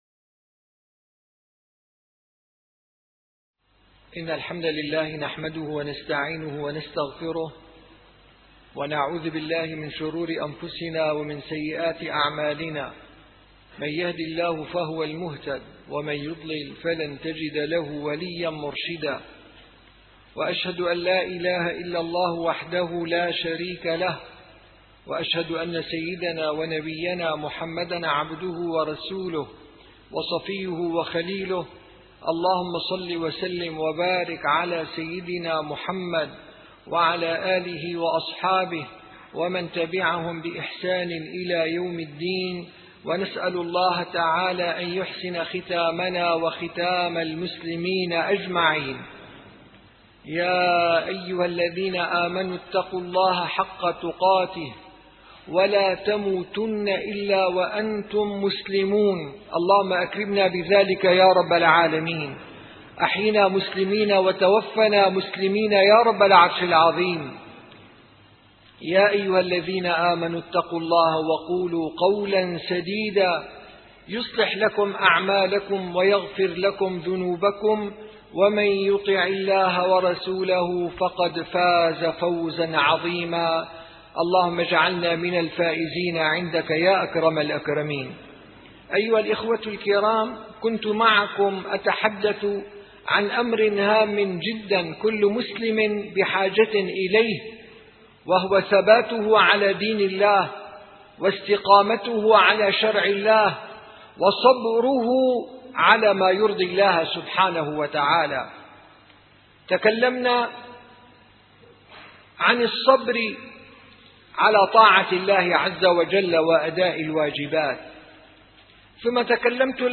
- الخطب - الصبر على المعاصي -2